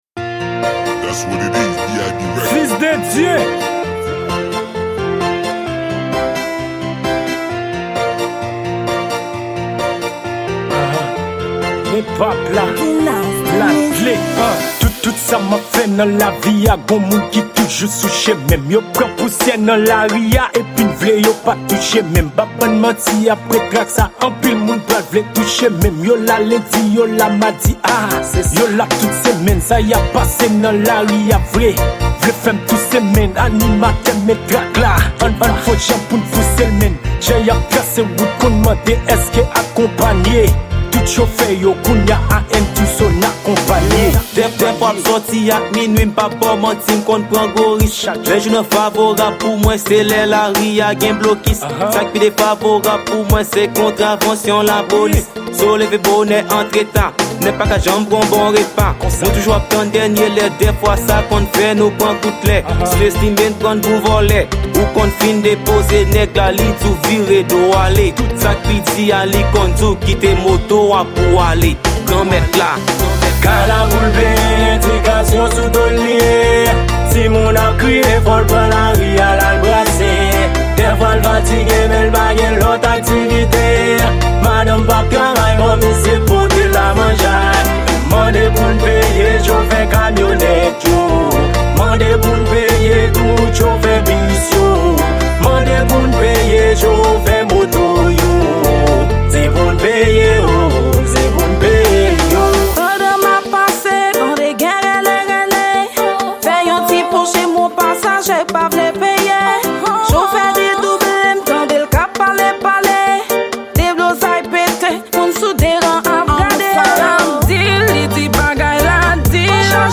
Genre:Rap.